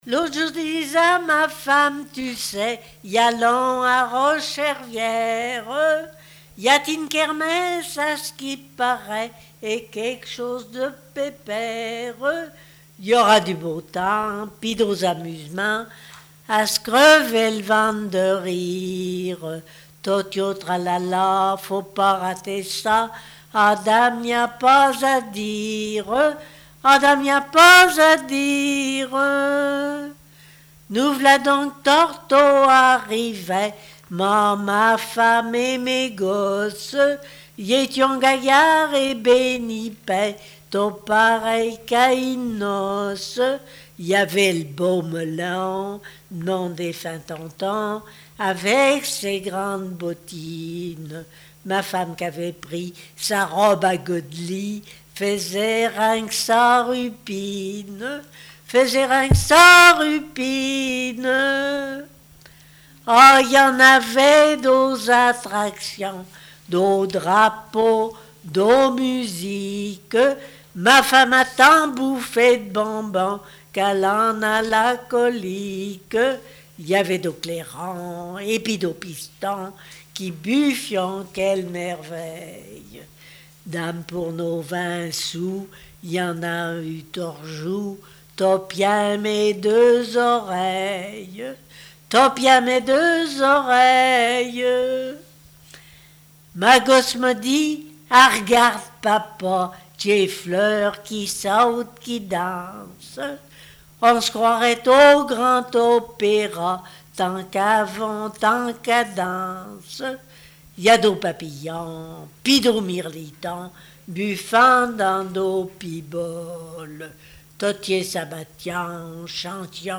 Langue Patois local
Pièce musicale inédite